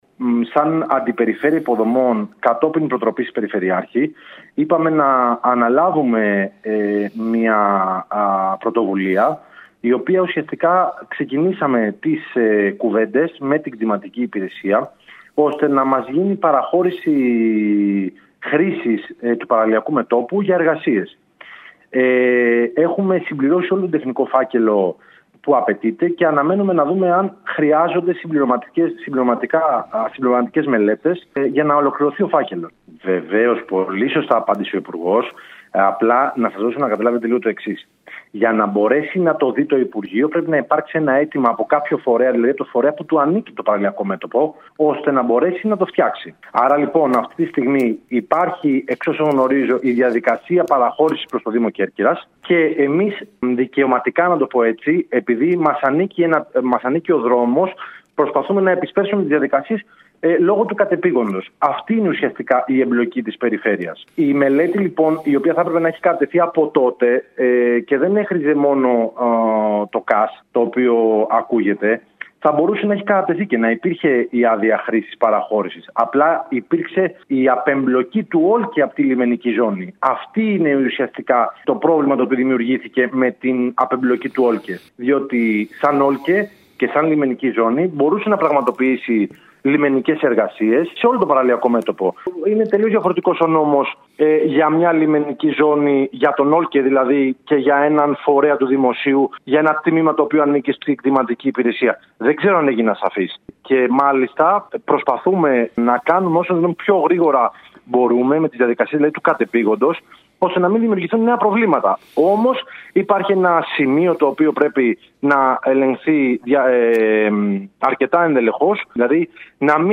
Στη διαδικασία ολοκλήρωσης του φακέλου που θα υποβάλλει στην Κτηματική Εταιρεία του Δημοσίου βρίσκεται η ΠΙΝ δηλώνει ο σημερινός αντιπεριφερειάρχης αρμόδιος για τα έργα Μανώλης Ορφανουδάκης.